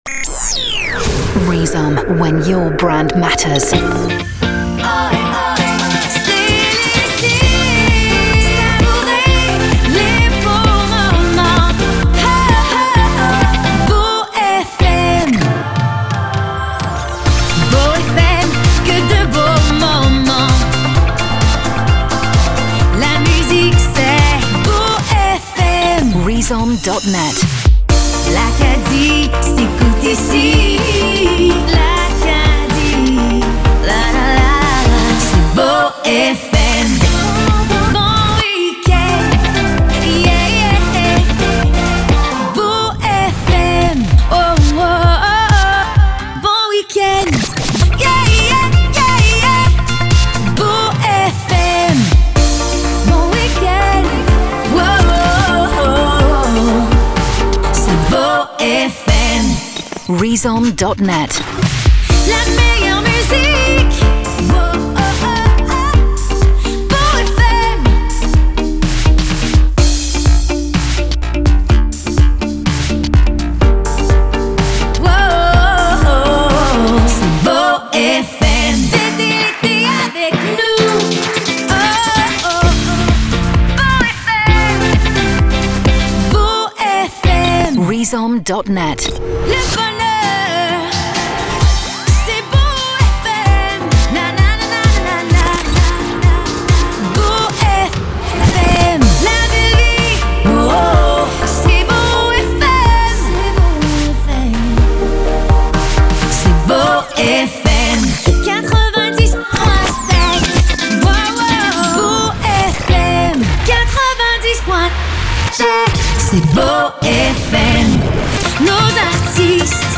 jingles Chantés